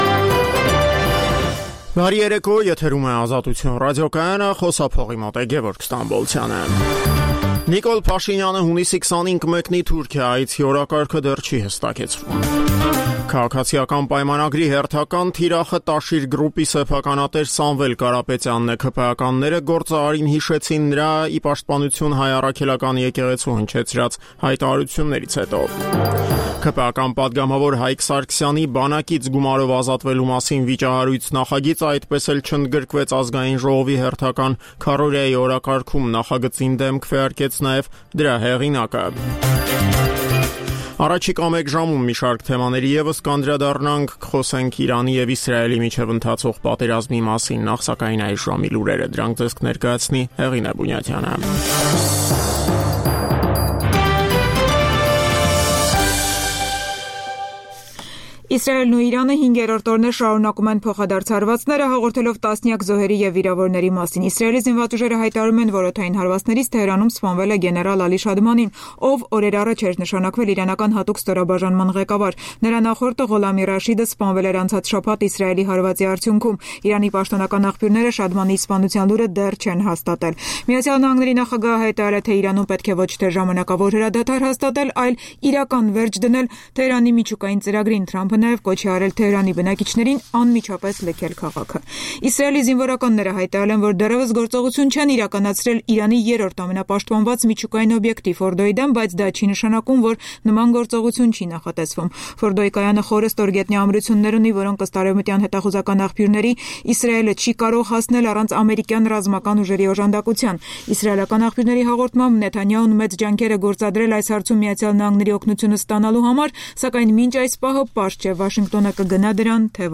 «Ազատություն» ռադիոկայանի օրվա հիմնական թողարկումը: Տեղական եւ միջազգային լուրեր, ռեպորտաժներ օրվա կարեւորագույն իրադարձությունների մասին, հարցազրույցներ, մամուլի տեսություն: